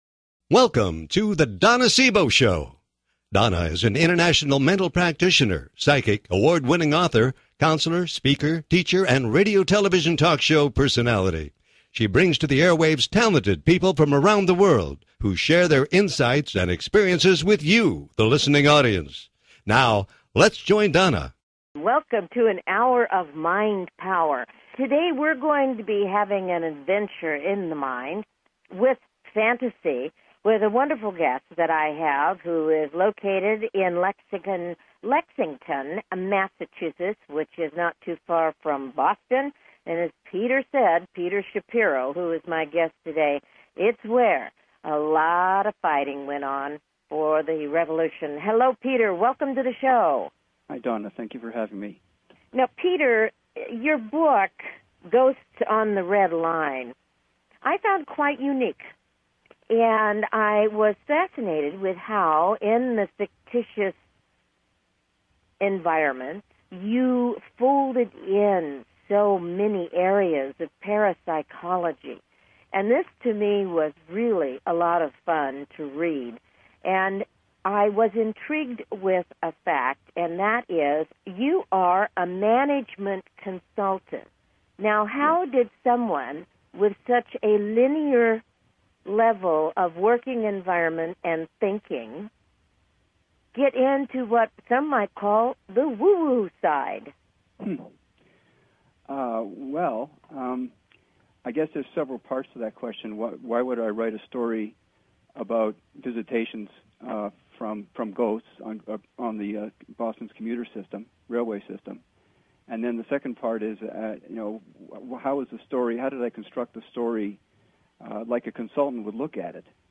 Her interviews embody a golden voice that shines with passion, purpose, sincerity and humor.
Callers are welcome to call in for a live on air psychic reading during the second half hour of each show.